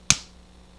SNAP.WAV